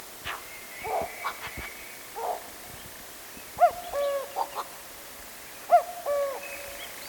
Common cuckoo
Cuculus canorus